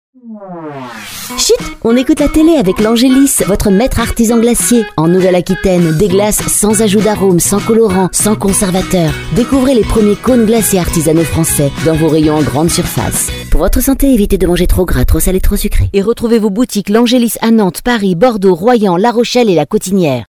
et voici le spot de notre partenaire